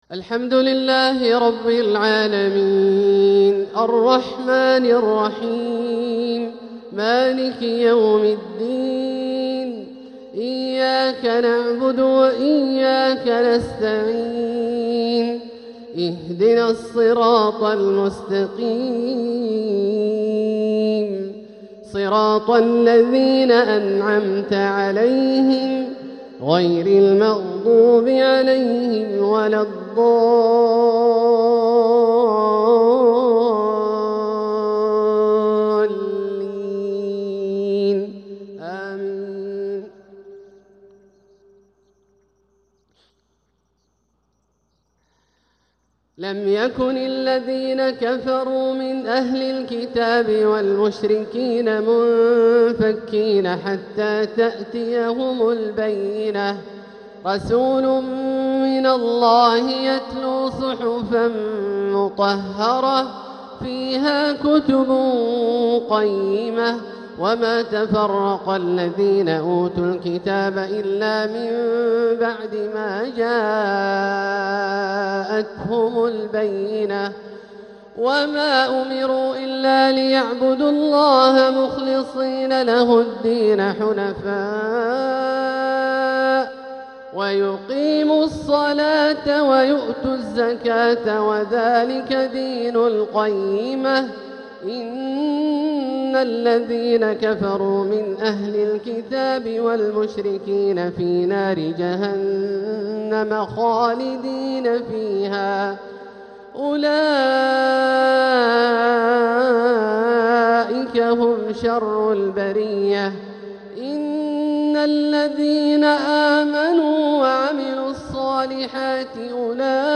تلاوة لسورتي البينة و الزلزلة | مغرب الثلاثاء 11 صفر 1447هـ > ١٤٤٧هـ > الفروض - تلاوات عبدالله الجهني